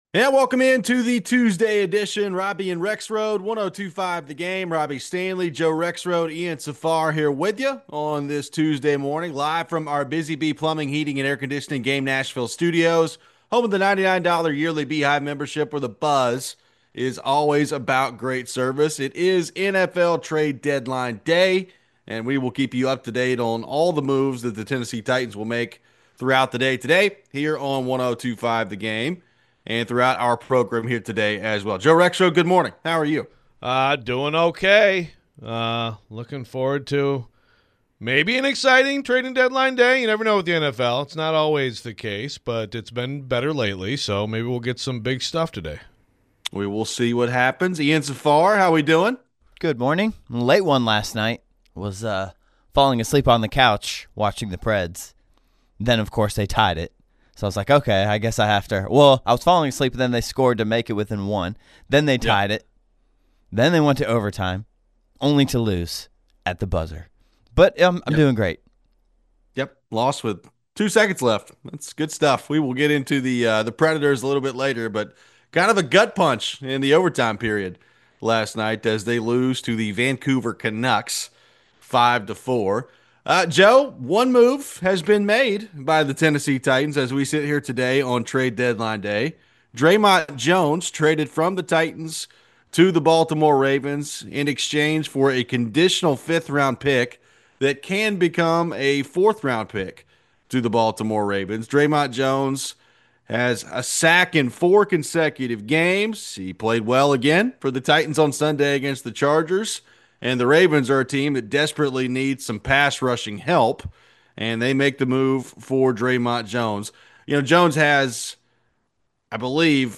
We head to your phones.